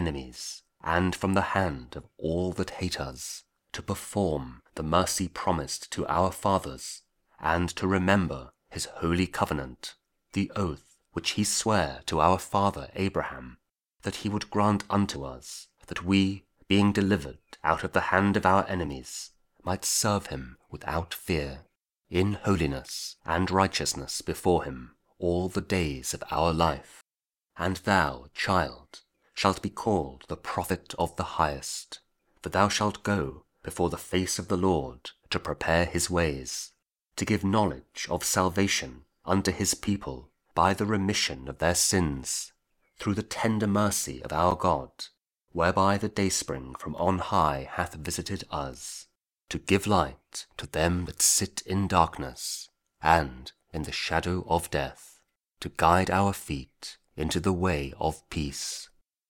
Luke 1: 67-79 – 24th December Weekday (Audio Bible, Spoken Word)